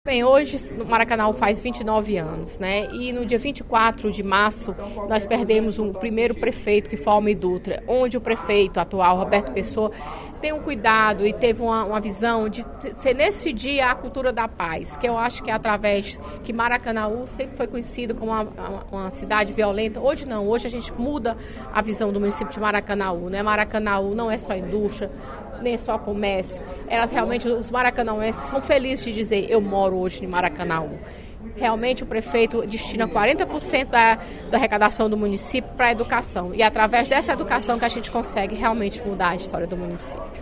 A deputada Fernanda Pessoa (PR) ressaltou, na sessão plenária desta terça-feira (06/03) da Assembleia Legislativa, os 29 anos de emancipação política de Maracanaú, município da Região Metropolitana de Fortaleza.